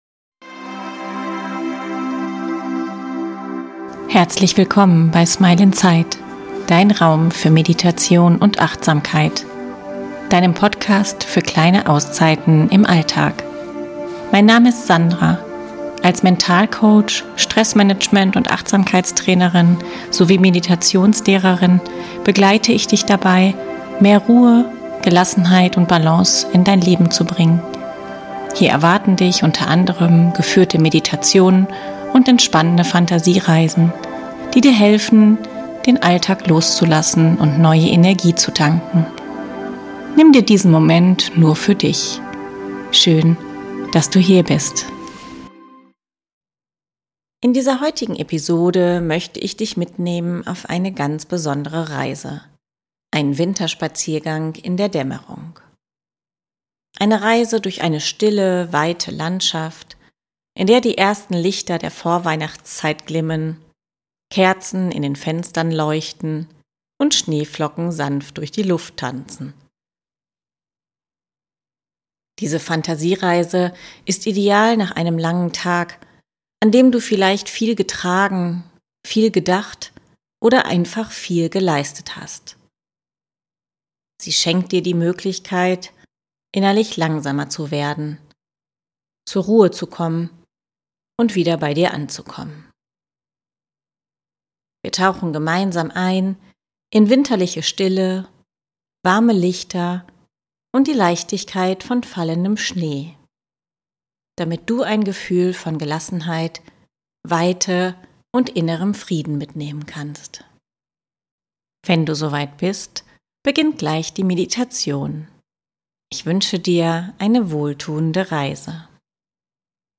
In dieser Folge erwartet dich eine entspannende Fantasiereise durch einen ruhigen Winterabend.